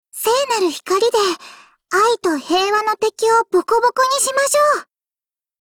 碧蓝航线:小光辉语音
Cv-20709_battleskill.mp3